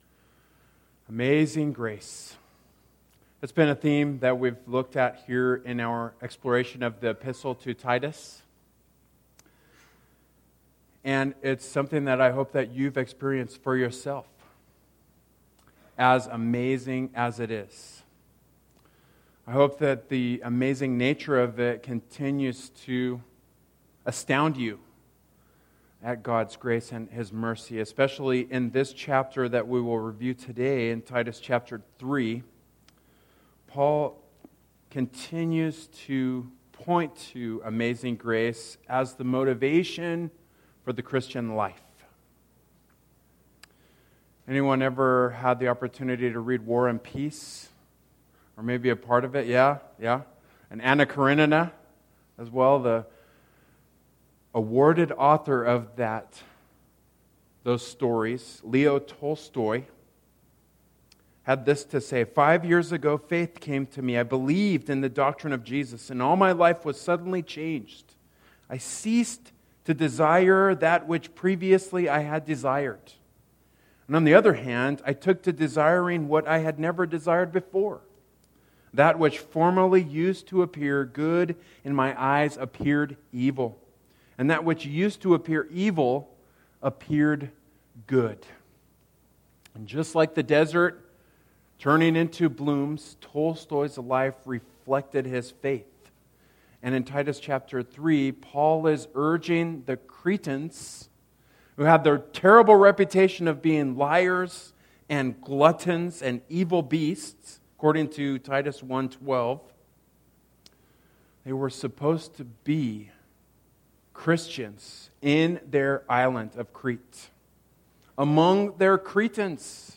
Passage: Titus 3:4-7 Service Type: Worship Service